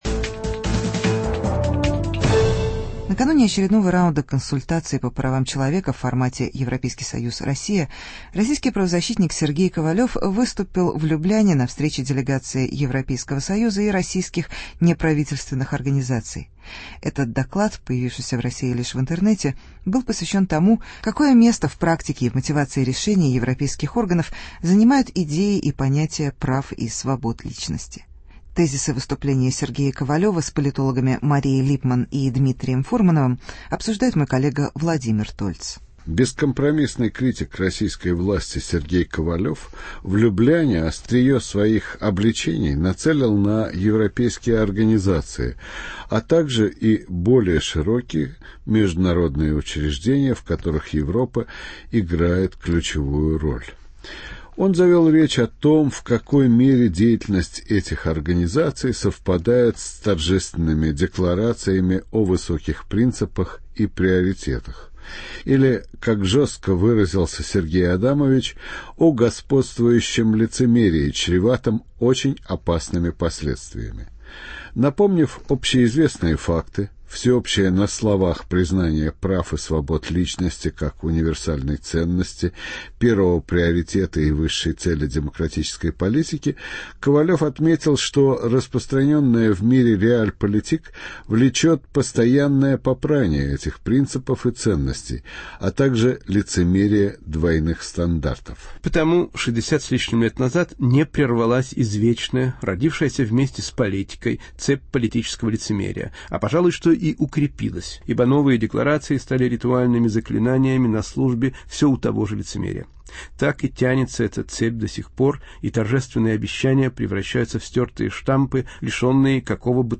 Программное выступление Сергея Ковалева о правах человека